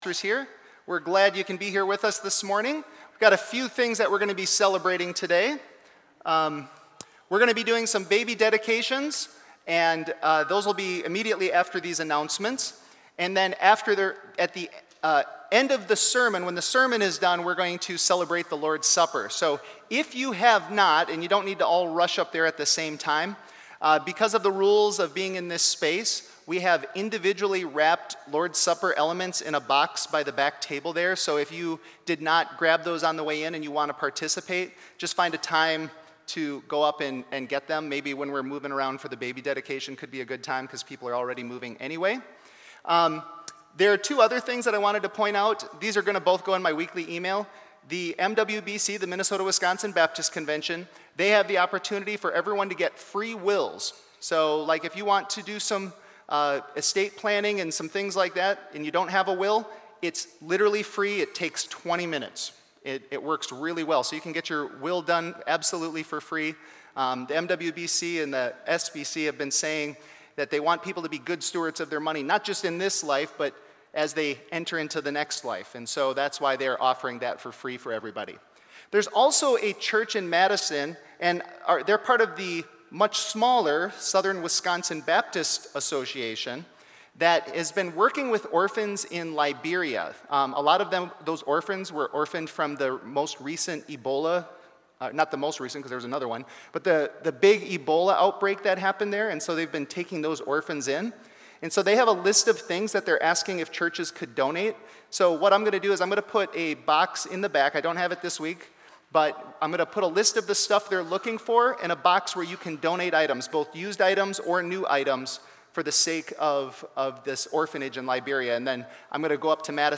John 8:12-20 Service Type: Sunday Worship